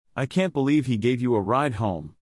High-energy emotions like happiness, excitement, fright and annoyance usually use a rising intonation.